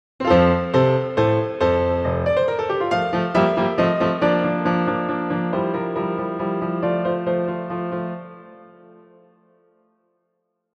This piece begins with what is called a “tonic pedal”, so named because the pedal point is the tonic of the key (the tonic is the “home” or root pitch of the scale, such as D in D major of G in G minor).
The chord progression of this opening statement is an elaborated I IV V I, but notice that the bass maintains a constant F. Although it is a non-root chord tone of IV, F is a completely foreign pitch in the V chord of C7.